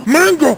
hitsound_retro4.wav